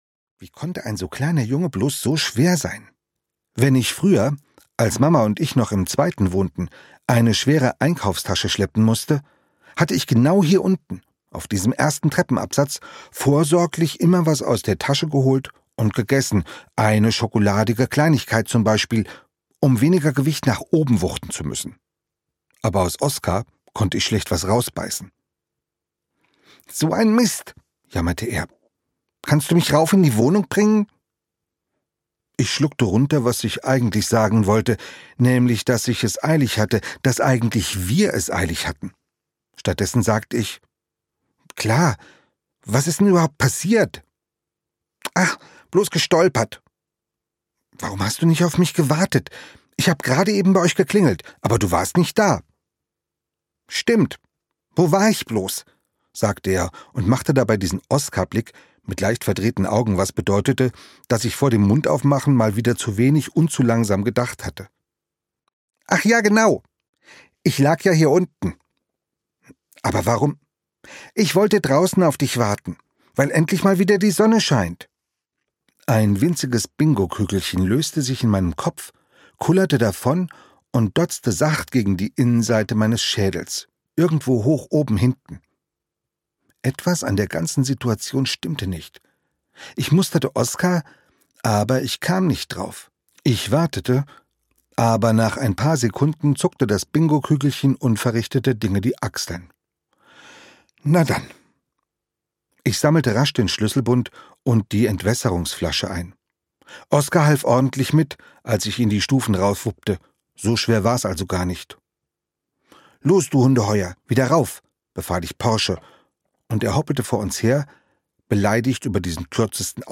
Rico und Oskar 5: Rico, Oskar und das Mistverständnis - Andreas Steinhöfel - Hörbuch